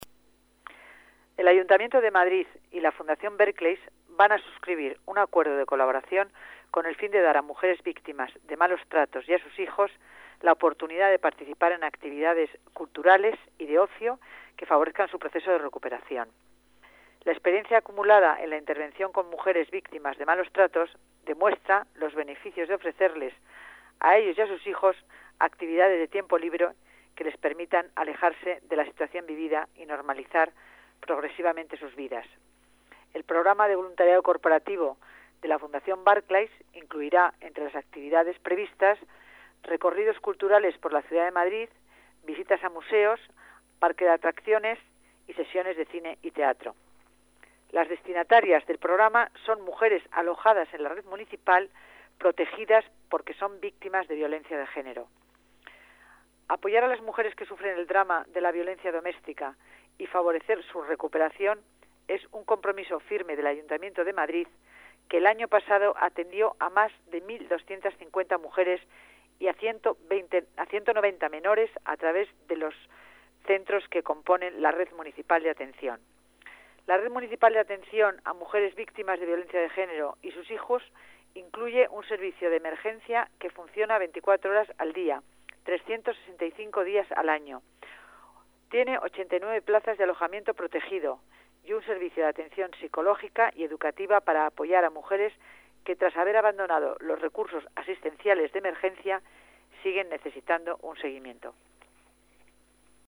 Nueva ventana:Declaraciones de la delegada de Familia y Servicios Sociales, Concepción Dancausa